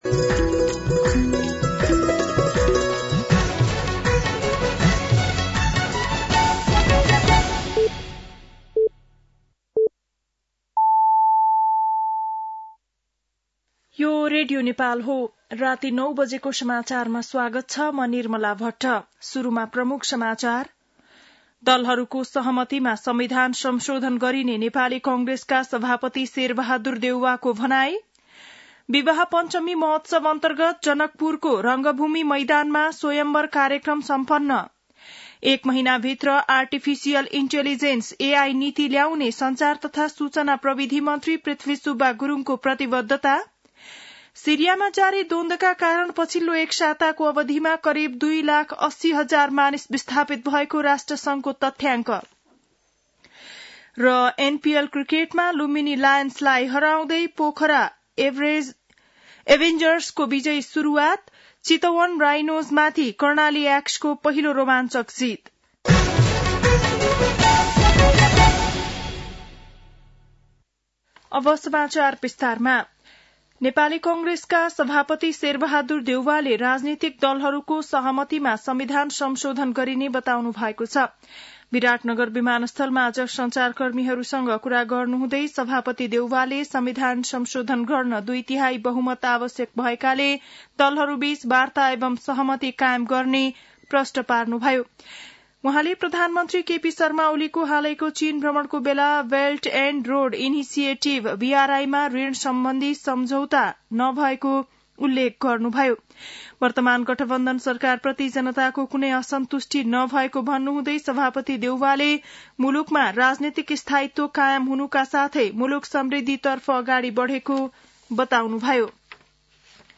बेलुकी ९ बजेको नेपाली समाचार : २२ मंसिर , २०८१